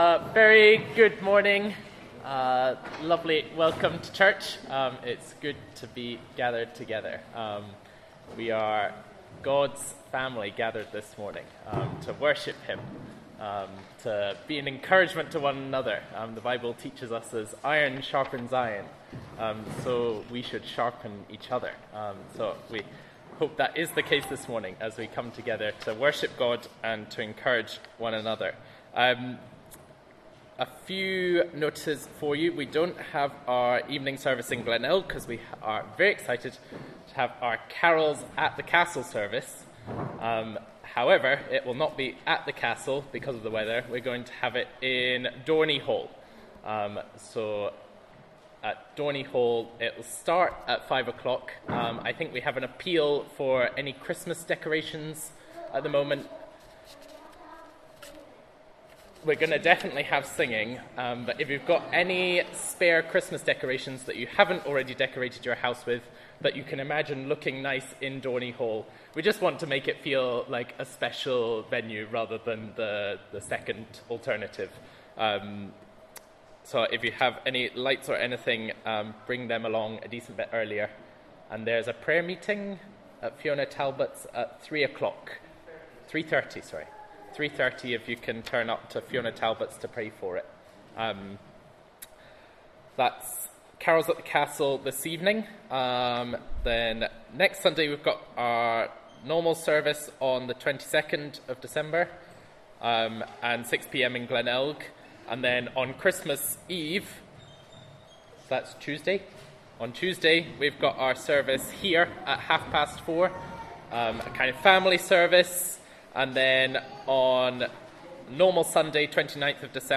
Service Type: Inverinate AM
Sunday-Service-15th-December-.mp3